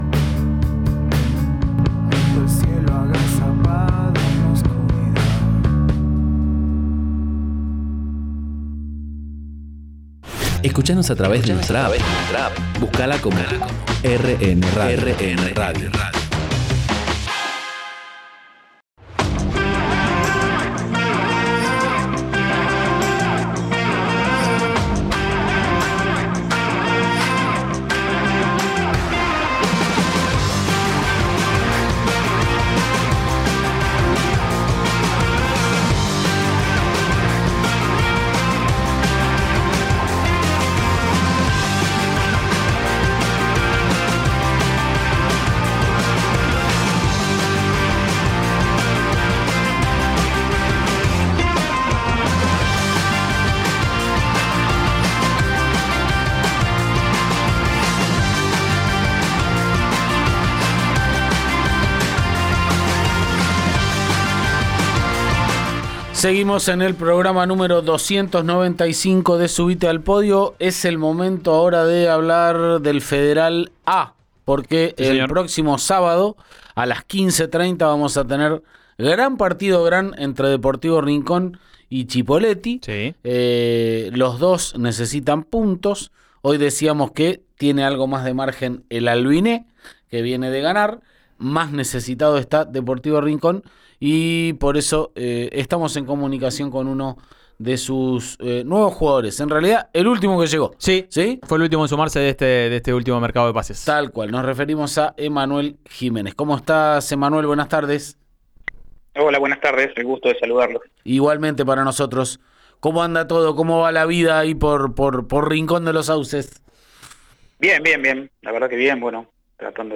Pude completar los 90 minutos en los dos partidos”, destacó en diálogo con “Subite al Podio” de Río Negro Radio.